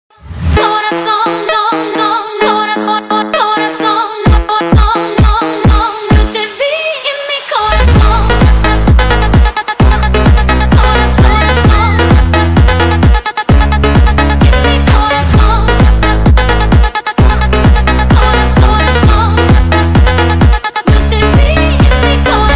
Shot in stunning 4K, this edit highlights every curve, roar, and moment that makes the M3 Competition a true performance icon.